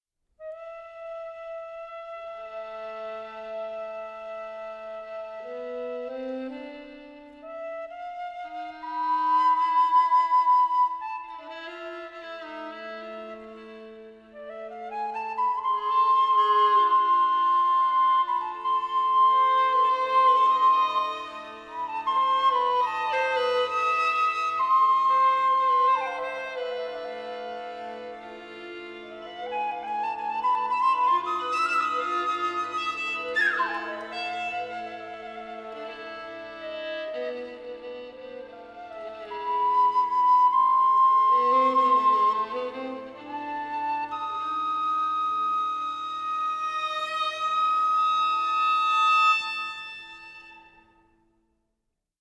soprano saxophone/orkon-flute
violin
- Suite for string quartet, saxophone and violin solo